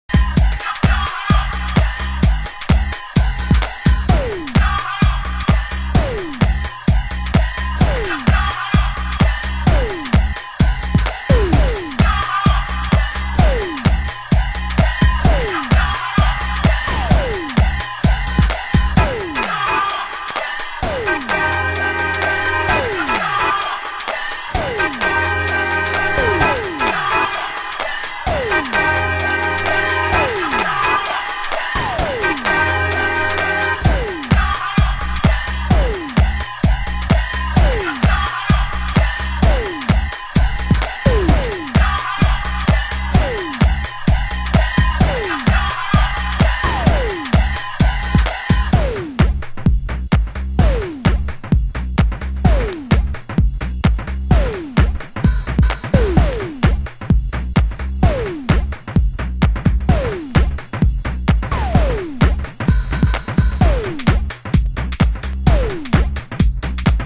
盤質：B面の前半部にスレ傷 /少しチリパチノイズ有